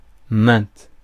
Prononciation
Prononciation France: IPA: [mɛ̃t] Accent inconnu: IPA: /mɛ̃/ Le mot recherché trouvé avec ces langues de source: français Les traductions n’ont pas été trouvées pour la langue de destination choisie.